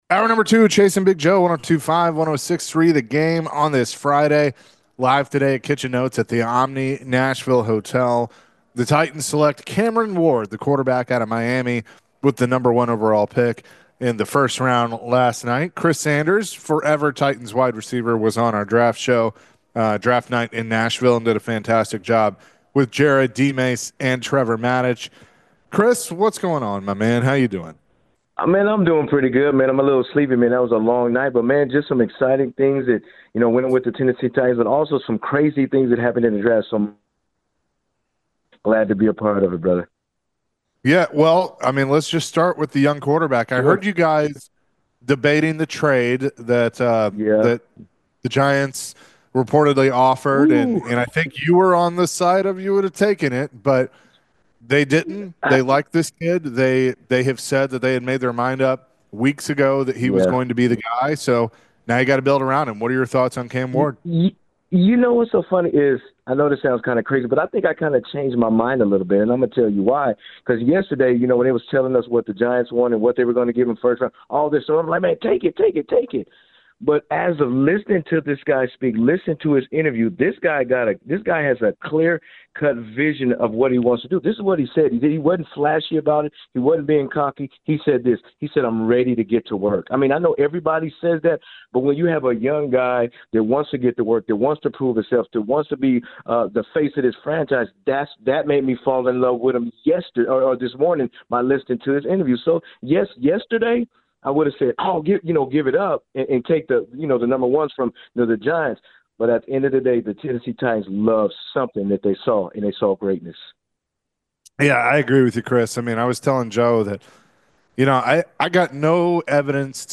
In the second hour, Forever Titans WR Chris Sanders joined the show to recap night one of the NFL Draft. Did the Titans come out as the big winners in the first round? Later in the hour, the guys answered more calls and texts about the Titans.